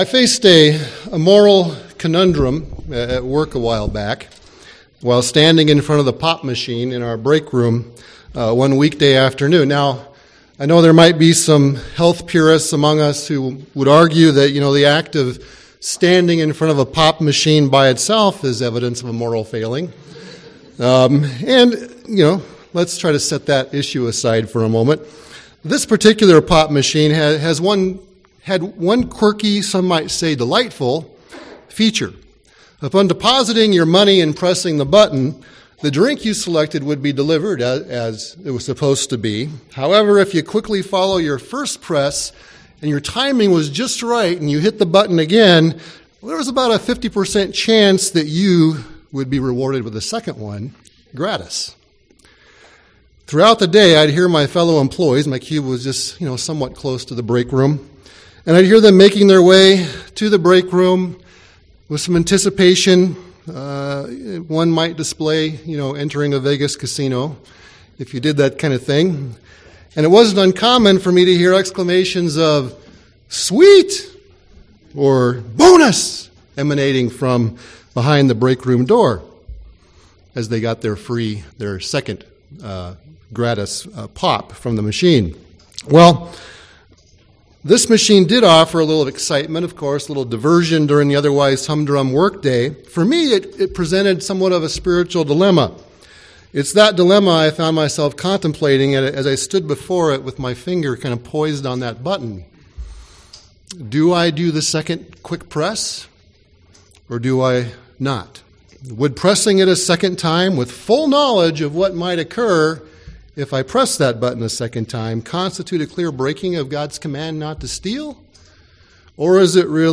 Sermons
Given in Twin Cities, MN